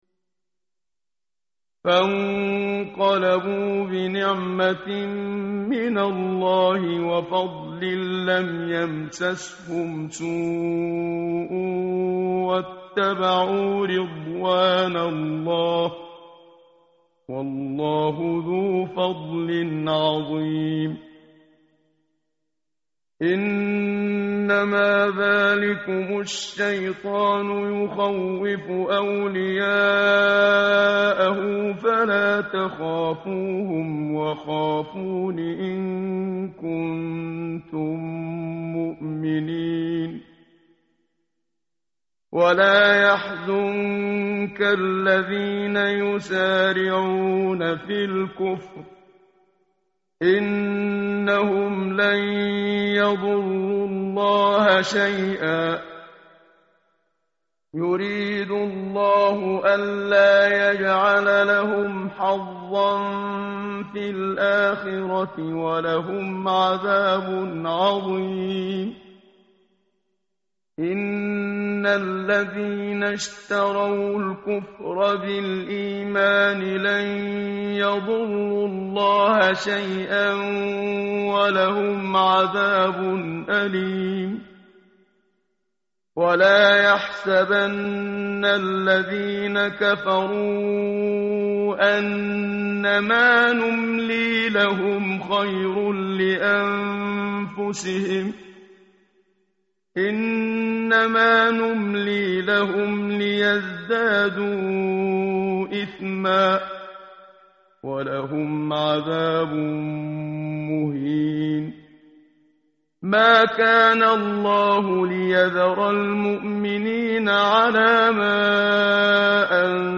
متن قرآن همراه باتلاوت قرآن و ترجمه
tartil_menshavi_page_073.mp3